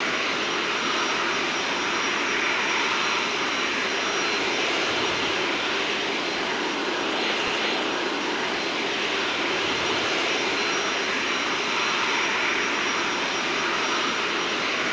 cfm-idleDistSide.wav